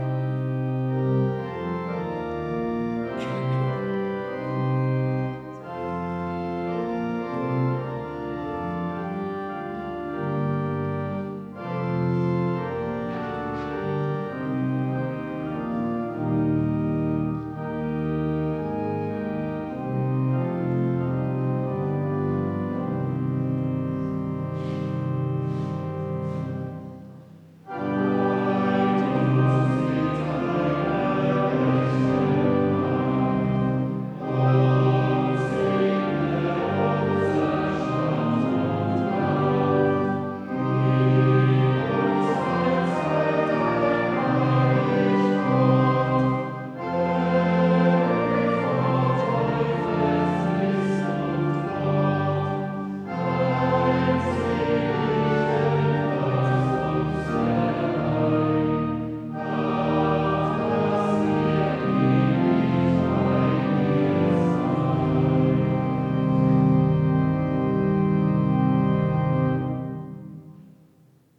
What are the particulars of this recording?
Audiomitschnitt unseres Gottesdienstes am Sonntag Invokavit 2025.